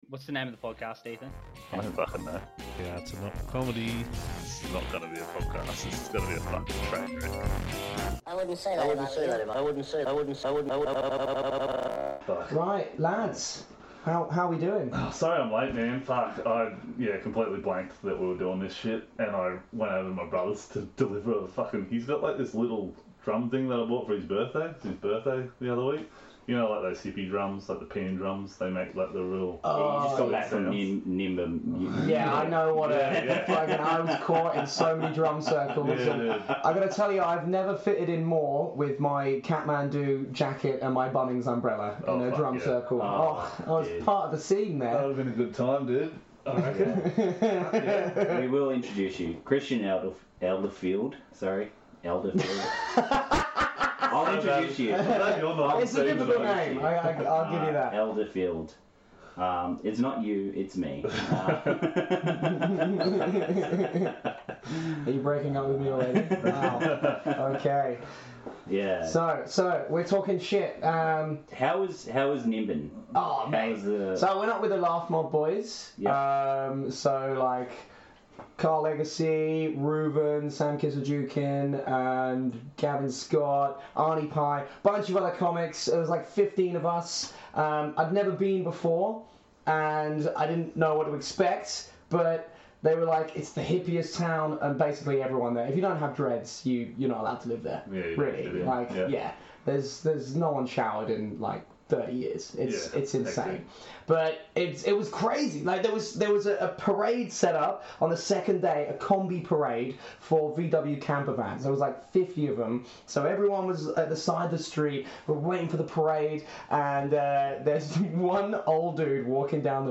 Shitty audio aside, actually a pretty fun one if you can tolerate the audio quality.